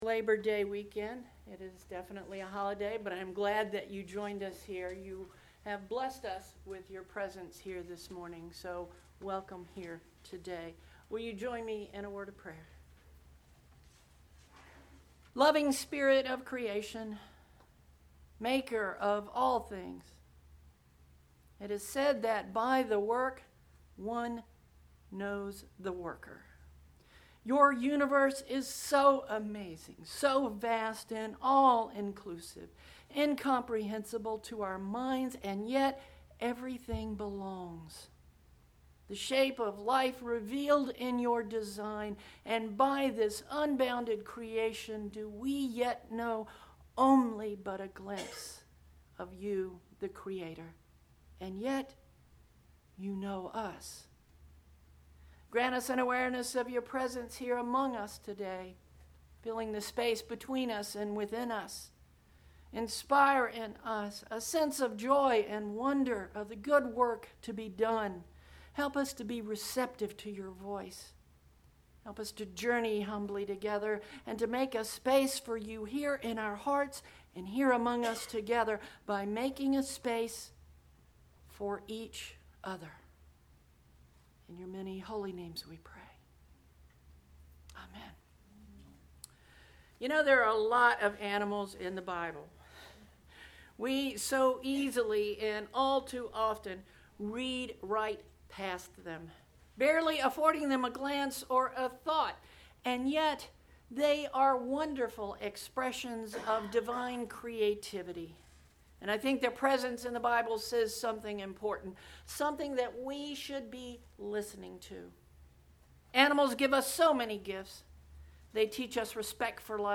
Sunday Sept 2nd – Summer Sermon Series: Animals of the Bible 5